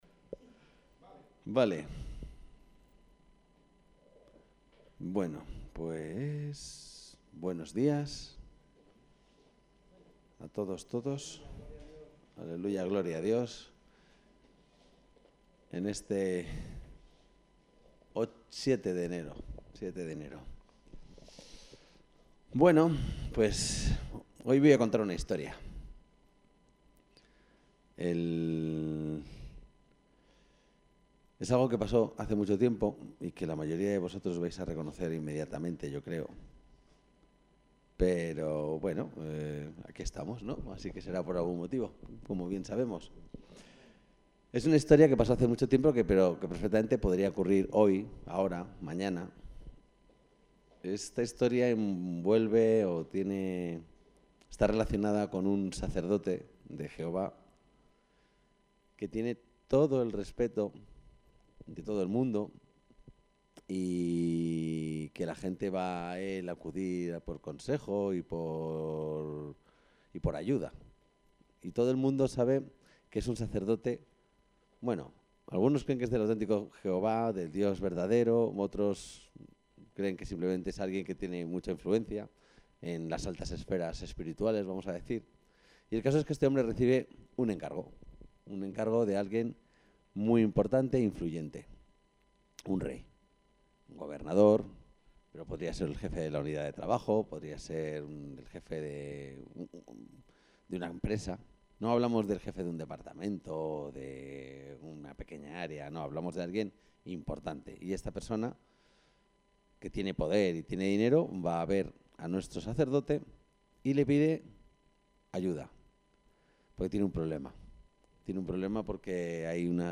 El texto de la predicación se puede encontrar aquí: Hacedores de la voluntad de Dios